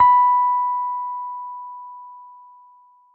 b4.mp3